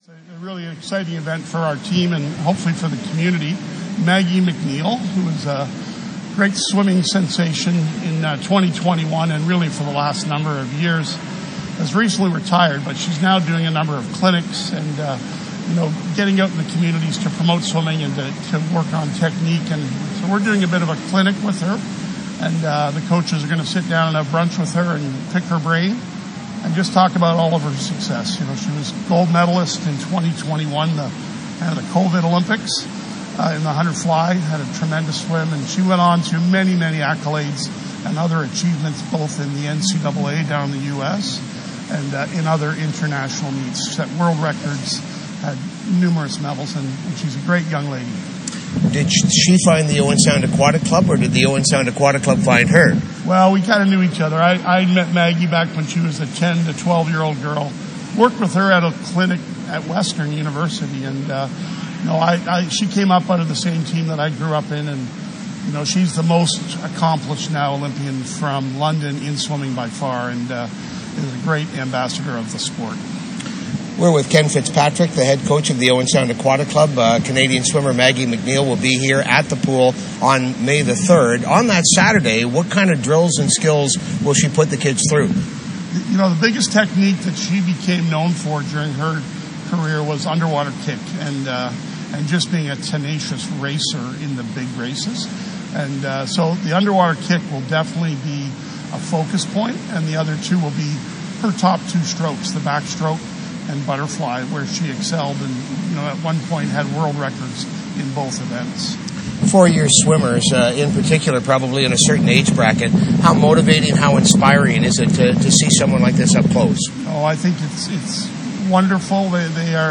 Maggie Mac Neil To Conduct Clinic. Interview